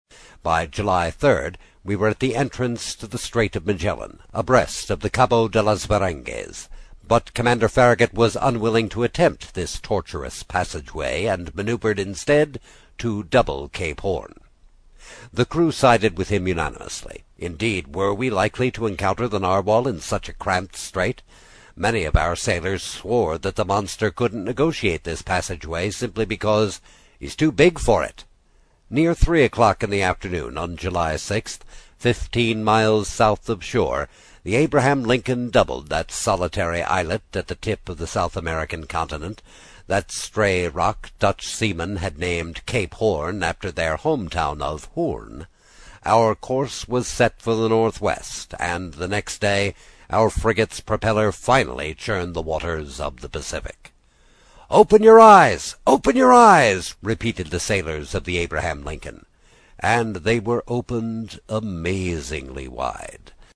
英语听书《海底两万里》第47期 第5章 冒险活动(2) 听力文件下载—在线英语听力室
在线英语听力室英语听书《海底两万里》第47期 第5章 冒险活动(2)的听力文件下载,《海底两万里》中英双语有声读物附MP3下载